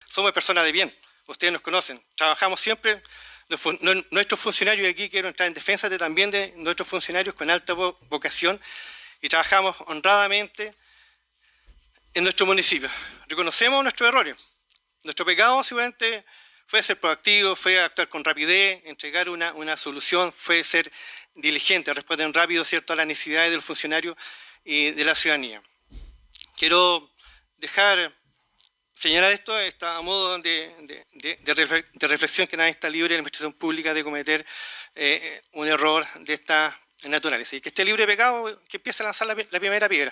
Sesión Concejo Municipal de Futrono | Carputa transmisión municipio